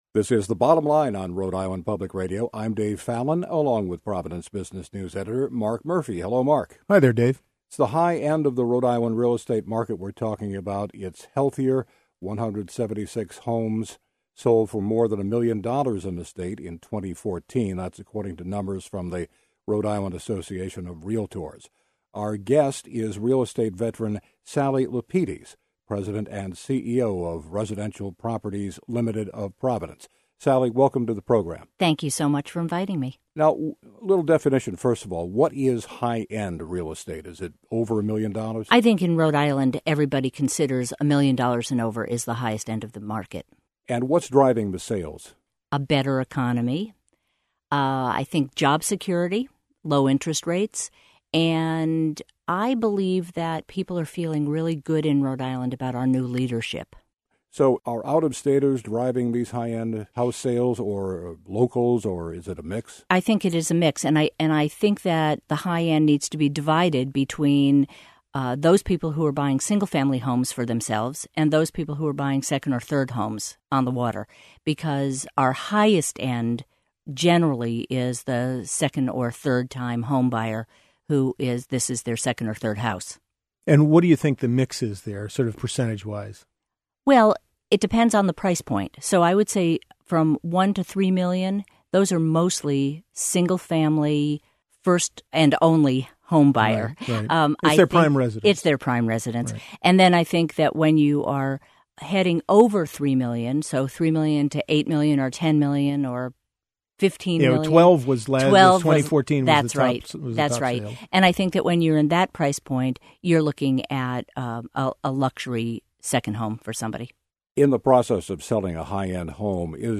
weekly business segment